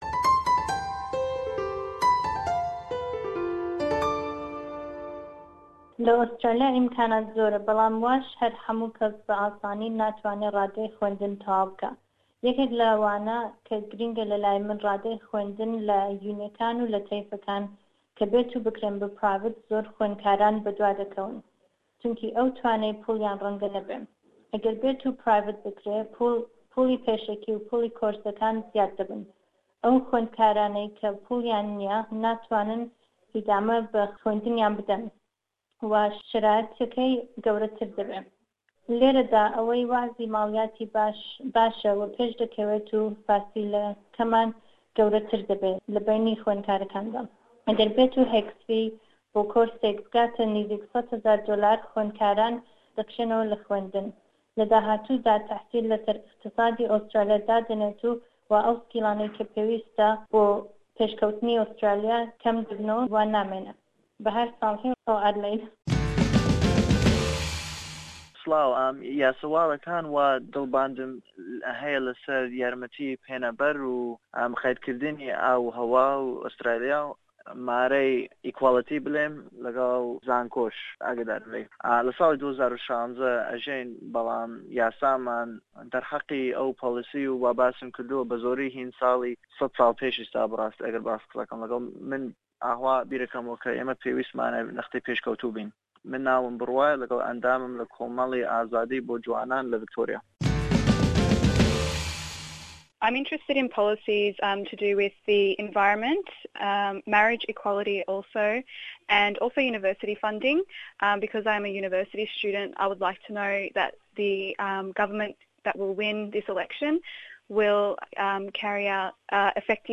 Em derbarî helbijartinên federaliye di 2/7/2016 de bi hin ciwanan re axifîn û me bîrûbaweriya wan sebaret bi helbijartinênên li pêsh pirsî.